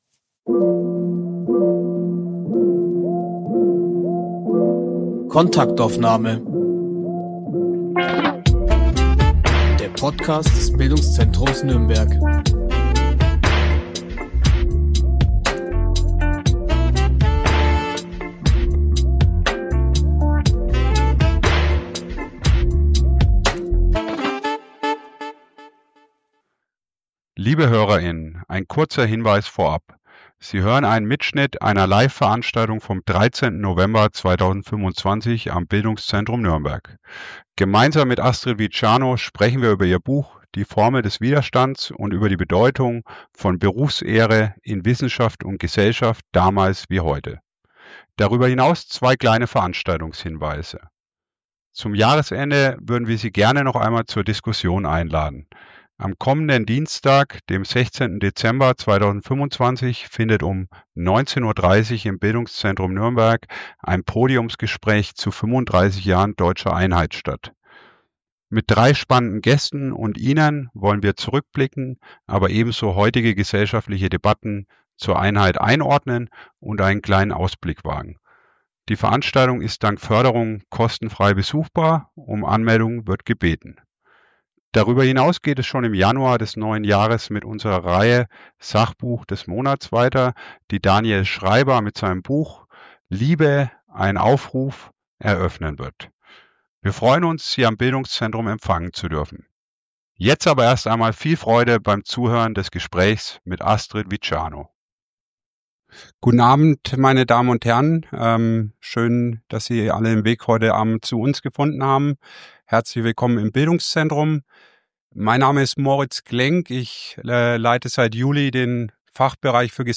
Mitschnitt einer Liveveranstaltung